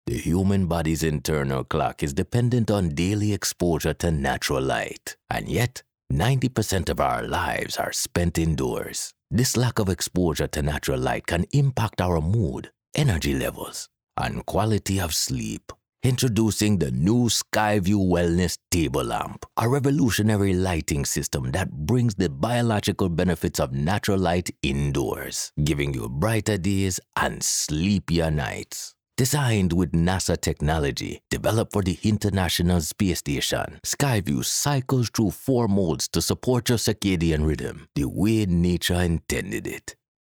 Authentic Jamaican and Caribbean Voiceover